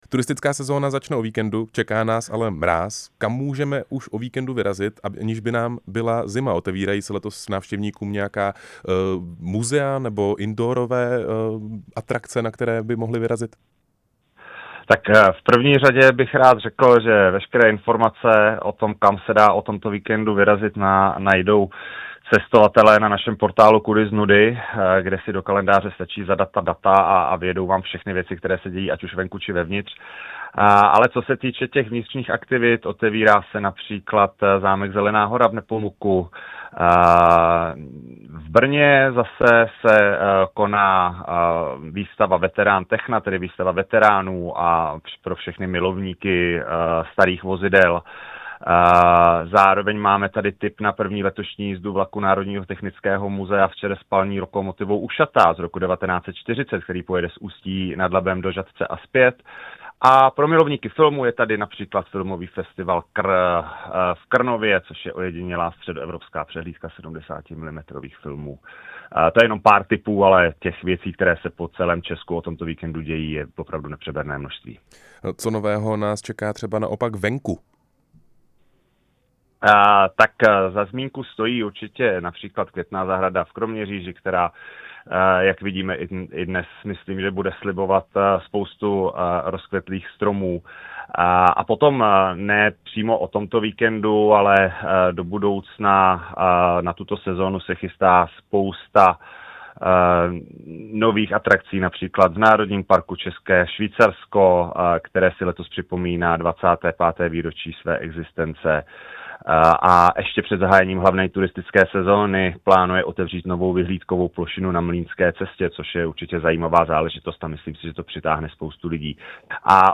Rozhovor s šéfem Czech Tourism Františkem Reismüllerem